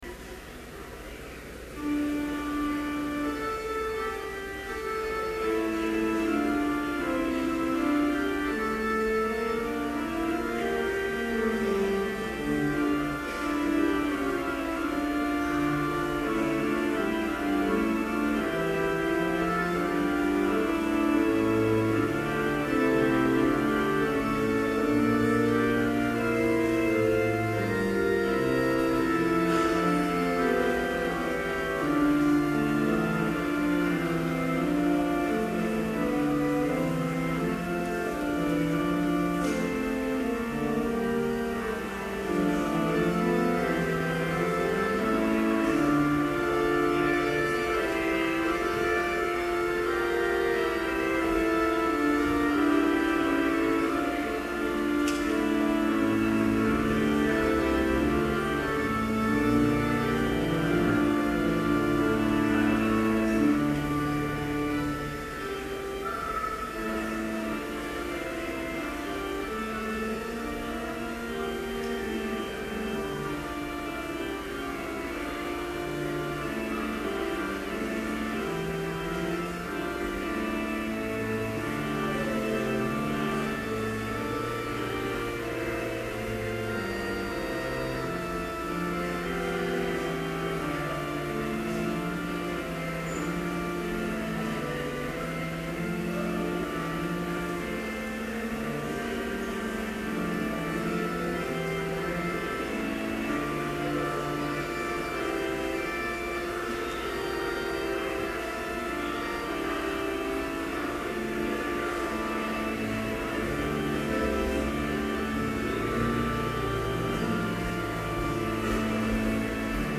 Complete service audio for Chapel - September 12, 2011